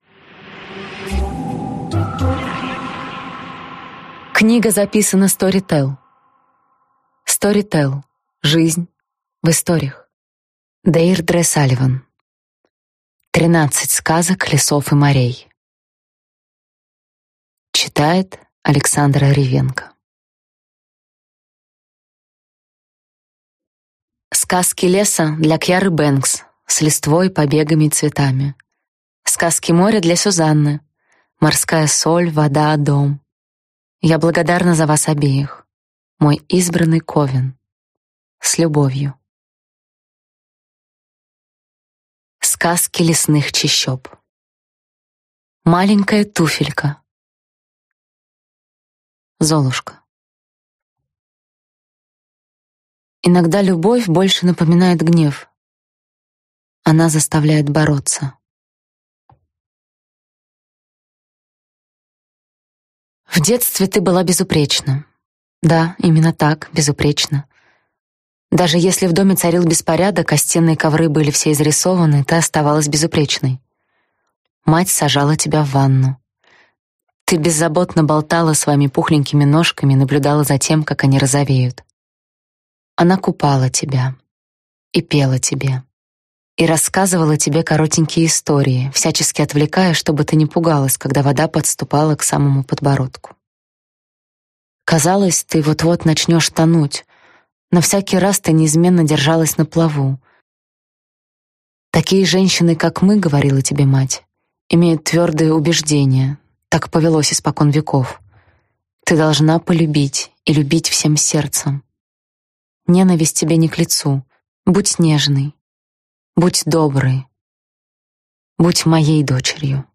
Аудиокнига 13 сказок лесов и морей | Библиотека аудиокниг